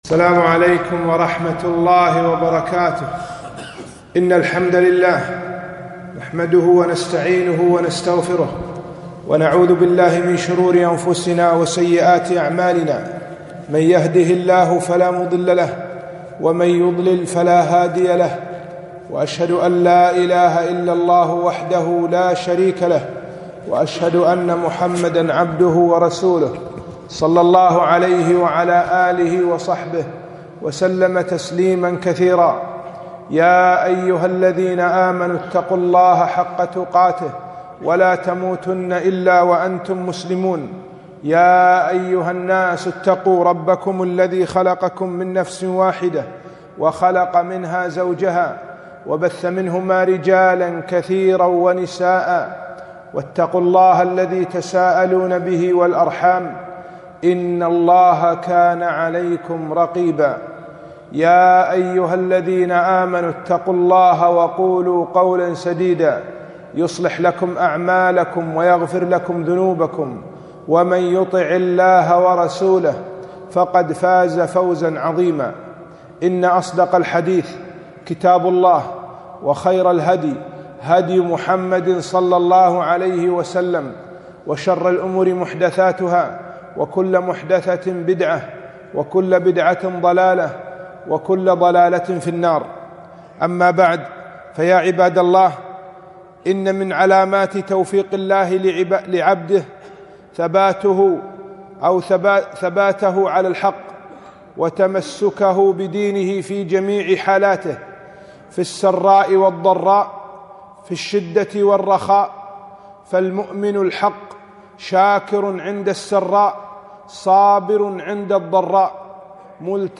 خطبة - فالله خير حافظا